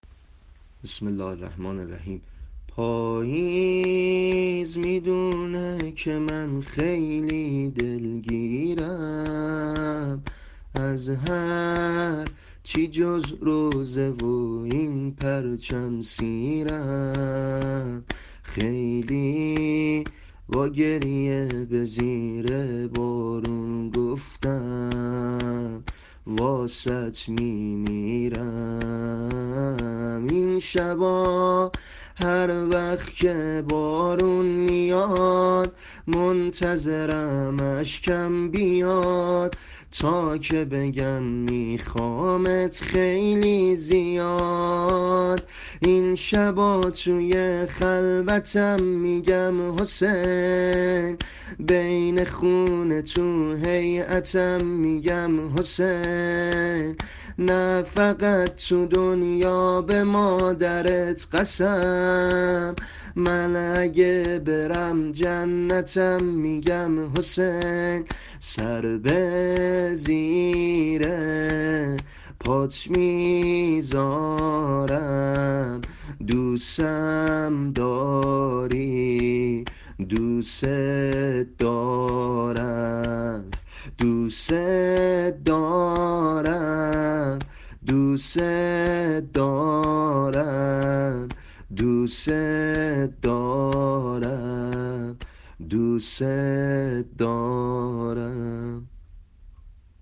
سبک شور سینه زنی مناجات با امام حسین